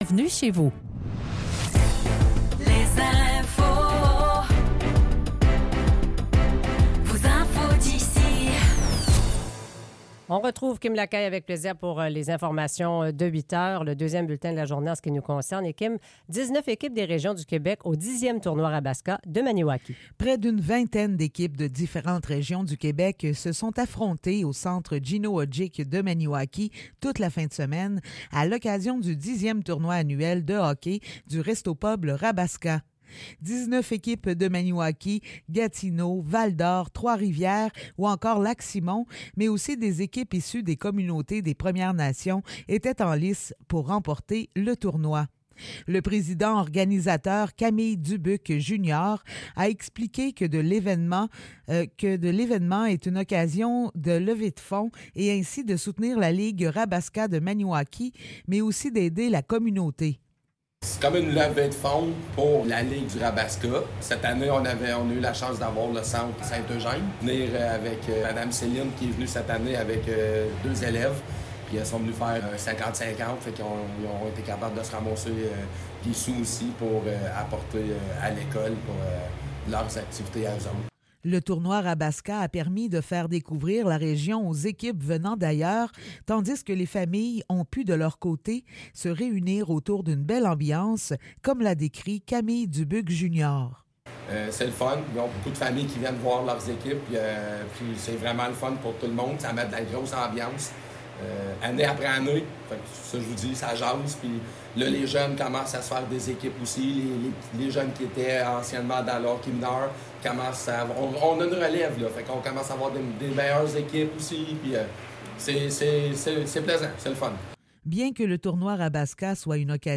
Nouvelles locales - 25 mars 2024 - 8 h